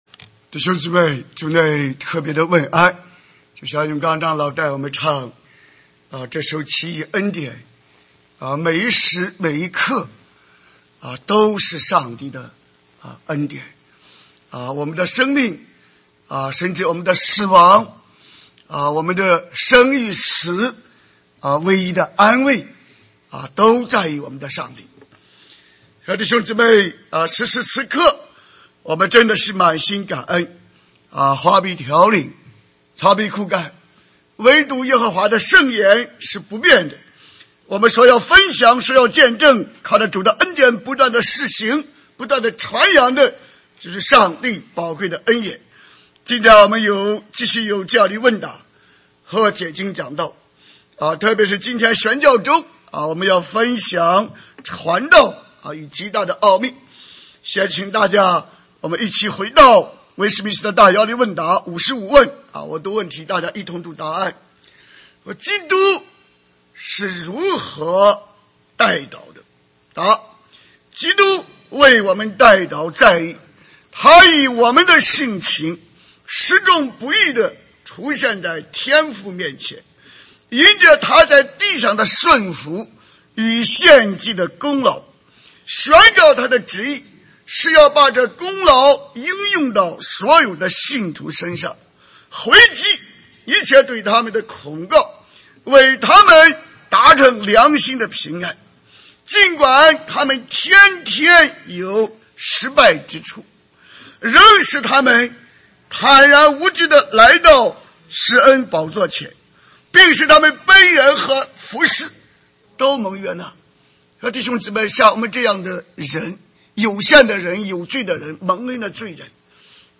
主日證道